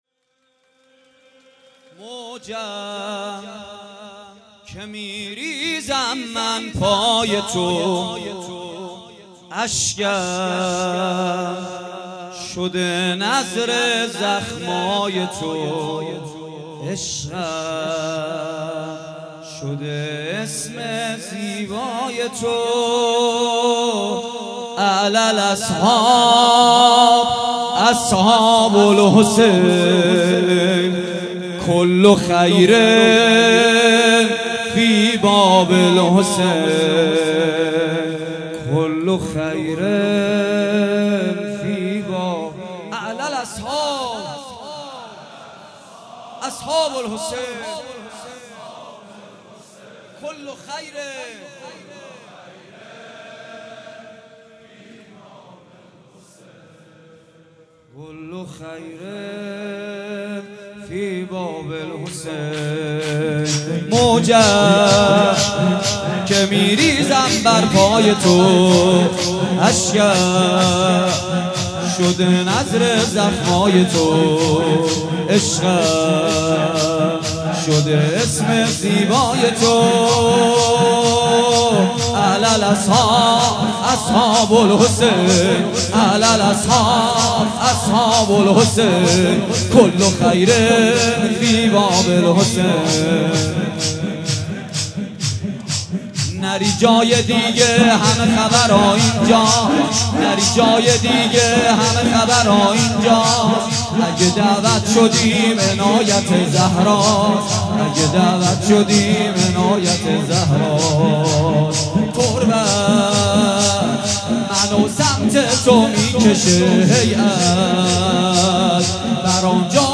شب اول محرم الحرام 1394 | هیات ریحانه النبی | کربلایی محمدحسین پویانفر